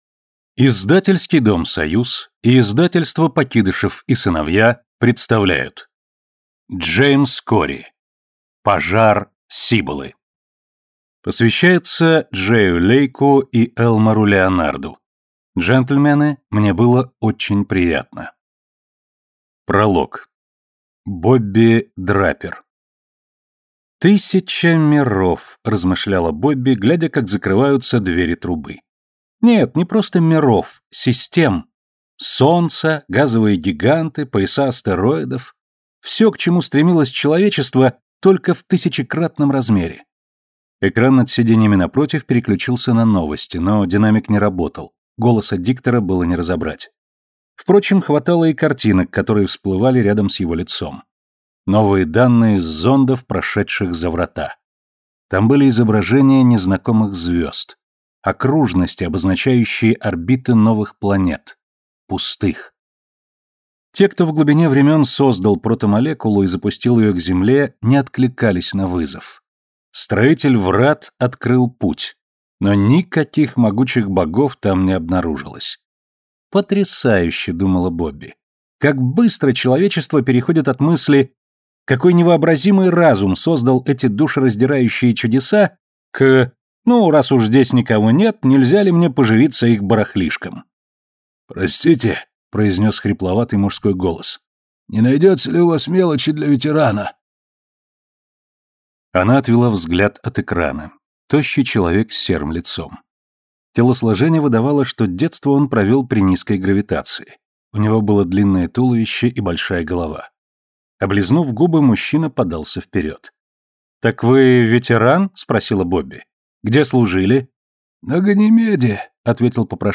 Аудиокнига Пожар Сиболы | Библиотека аудиокниг